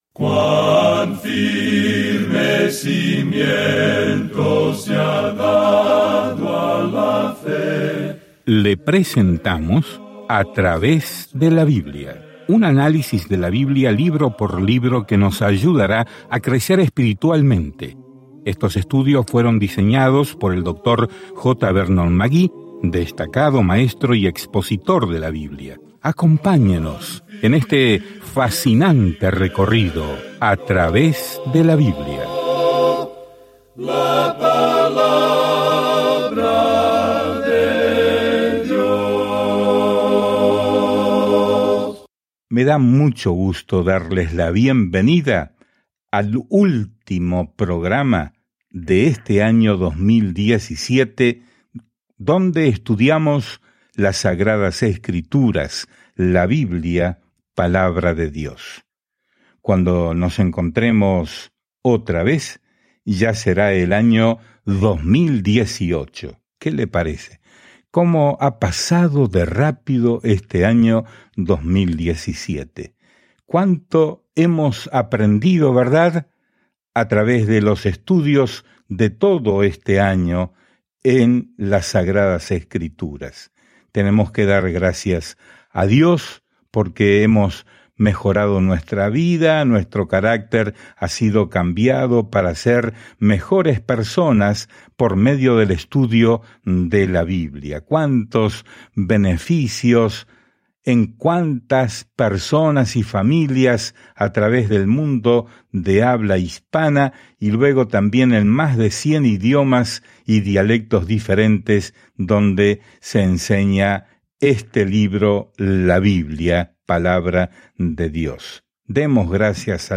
Escrituras ECLESIASTÉS 7 ECLESIASTÉS 8:1-15 Día 7 Comenzar este Plan Día 9 Acerca de este Plan Eclesiastés es una autobiografía dramática de la vida de Salomón cuando intentaba ser feliz sin Dios. Al viajar diariamente a través de Eclesiastés, escucha el estudio de audio y lee versículos seleccionados de la palabra de Dios.